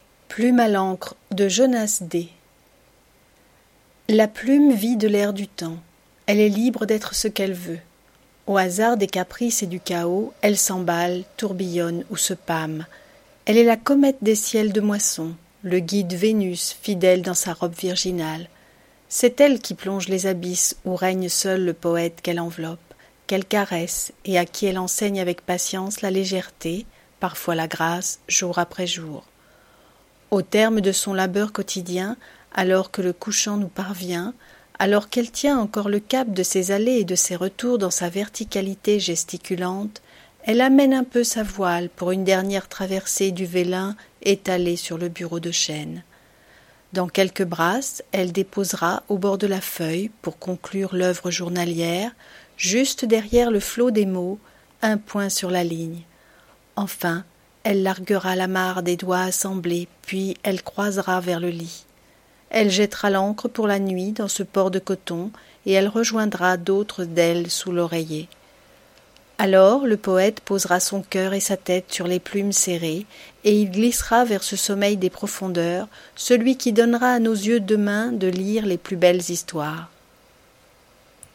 Lecture à haute voix
POEME